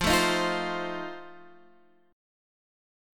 F7 Chord
Listen to F7 strummed